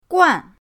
guan4.mp3